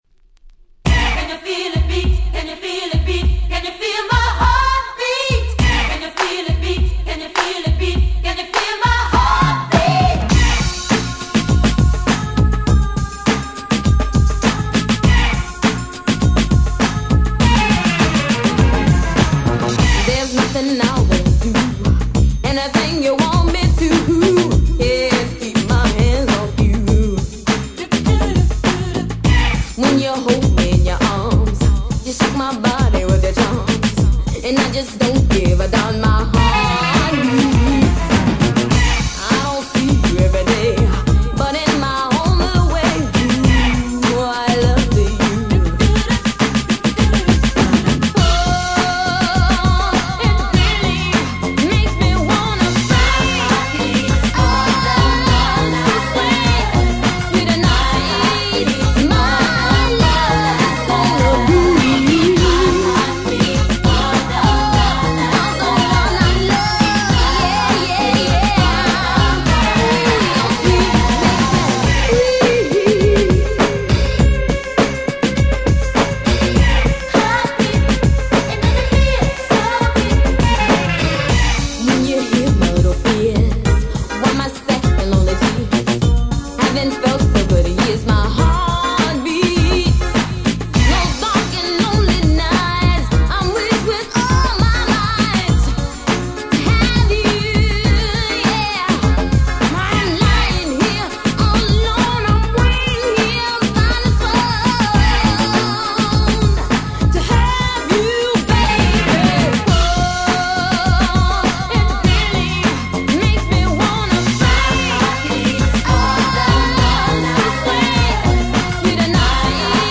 90' Smash Hit R&B !!
こちらの7''のバージョンは12''には未収録の頭が短いバージョンで実は非常に使い易かったり！